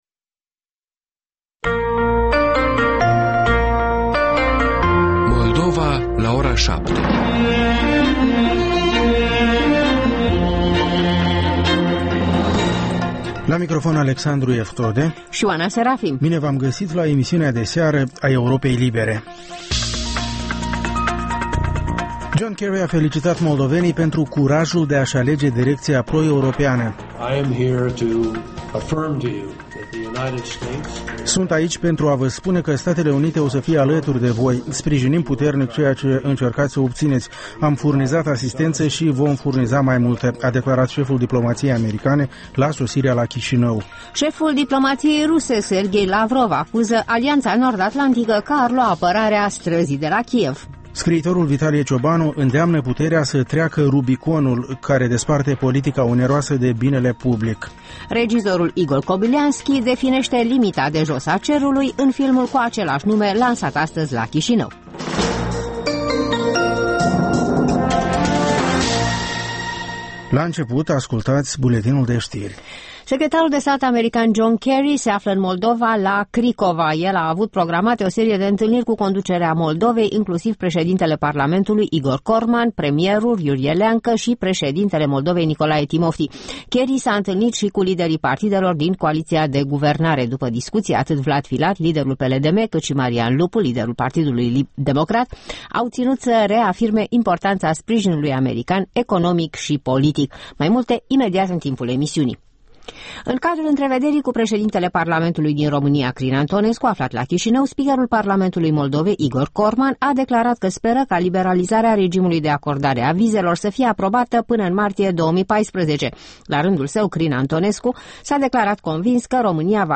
Ştiri, interviuri, analize şi comentarii.